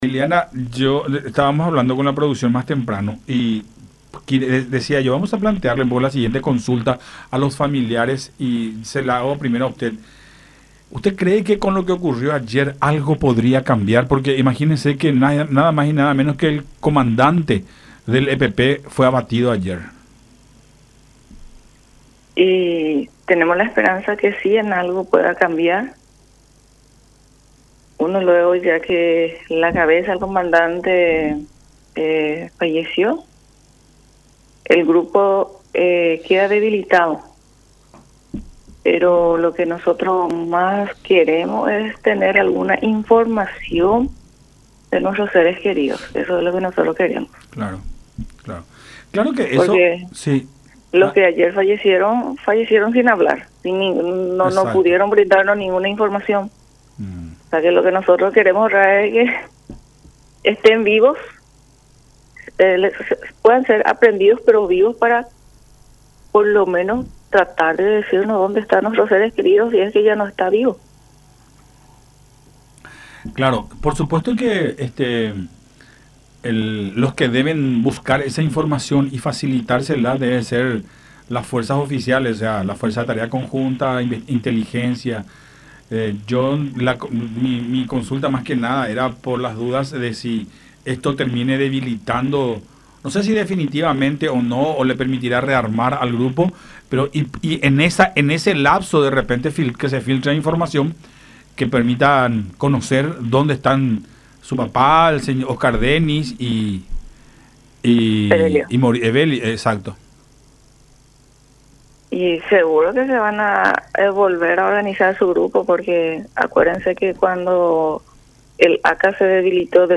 en diálogo con Nuestra Mañana a través de Unión TV y radio La Unión.